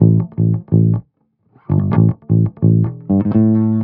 05 Bass Loop A.wav